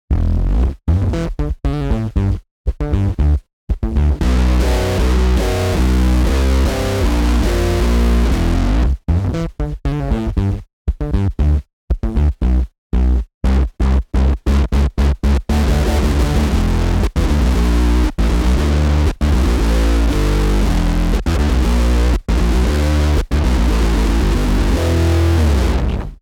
Lower instrument volumes create a clear, fuzzy overdrive, while full instrument volume sends the gain over the top for ripping riff and searing solo tones.
• Vintage-style octave fuzz optimized for bass
Dynamic Response
Lizard-KingBass-Octave-Fuzz-Dynamic-Response.mp3